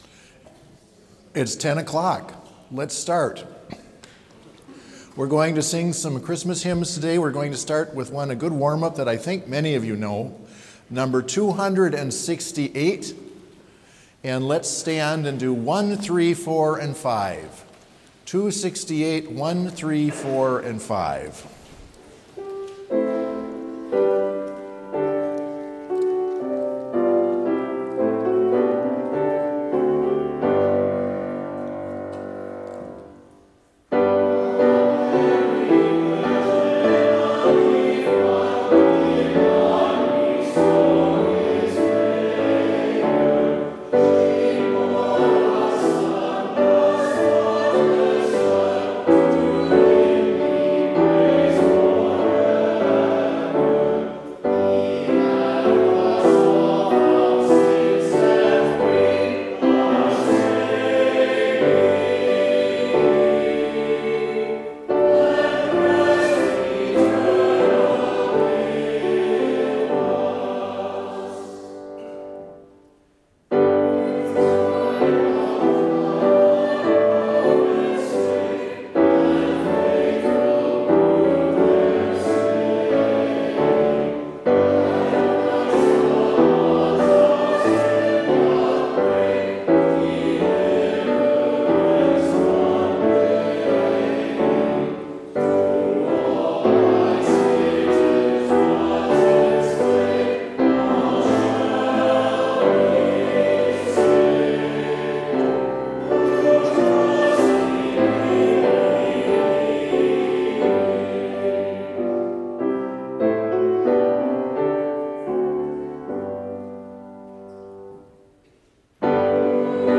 Complete service audio for Chapel - December 13, 2022
Order of Service